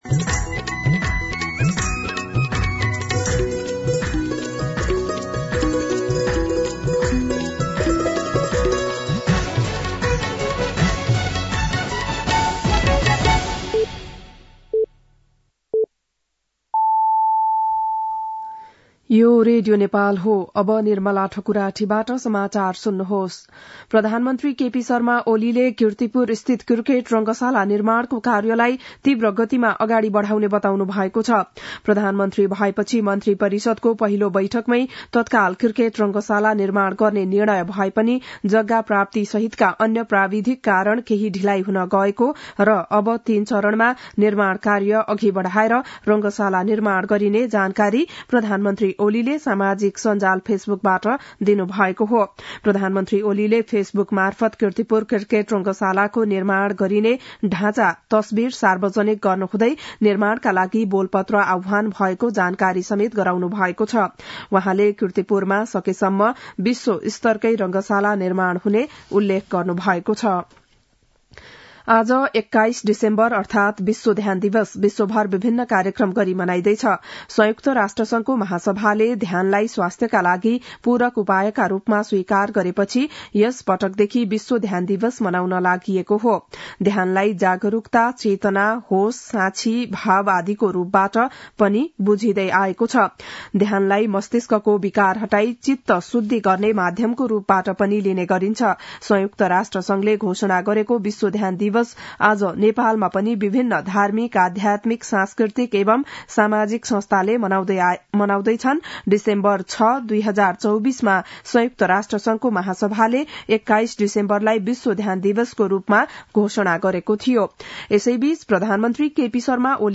बिहान ११ बजेको नेपाली समाचार : ७ पुष , २०८१
11-am-Nepali-News-1.mp3